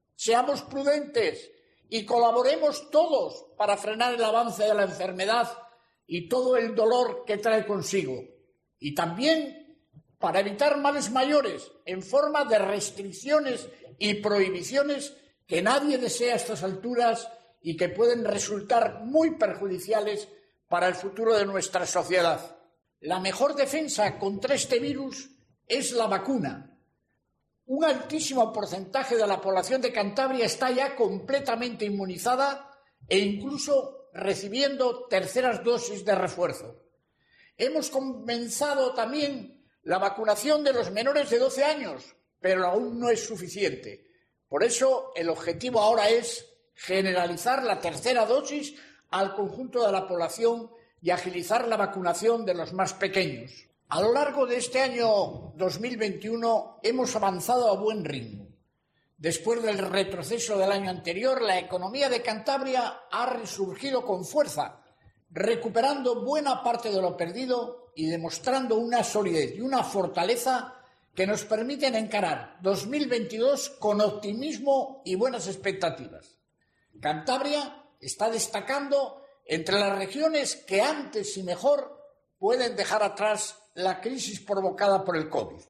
Extracto del mensaje de Navidad de Miguel Ángel Revilla